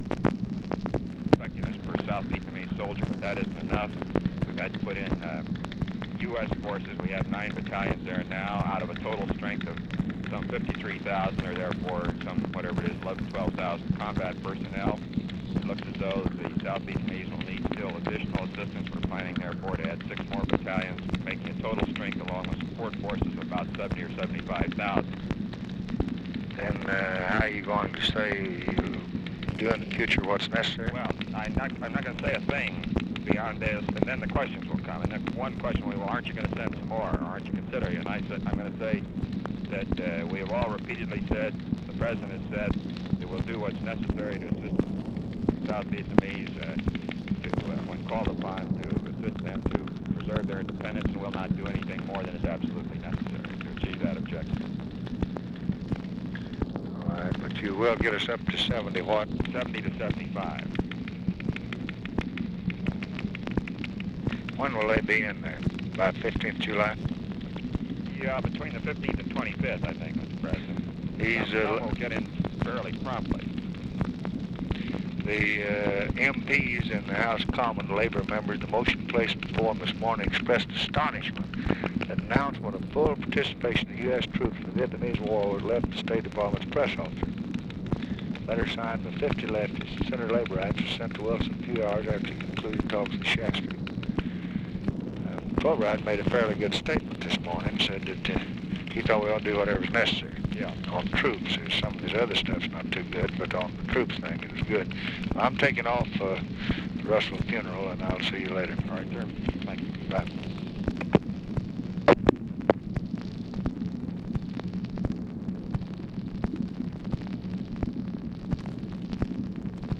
Conversation with ROBERT MCNAMARA, June 16, 1965
Secret White House Tapes